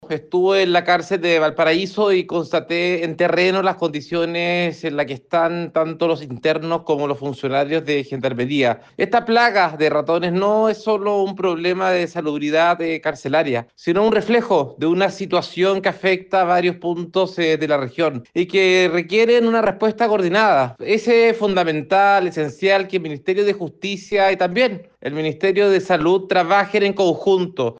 El diputado del distrito 7, Andrés Celis reaccionó sobre esta decisión judicial, destacando la preocupación tanto de internas, como de los funcionarios de Gendarmería.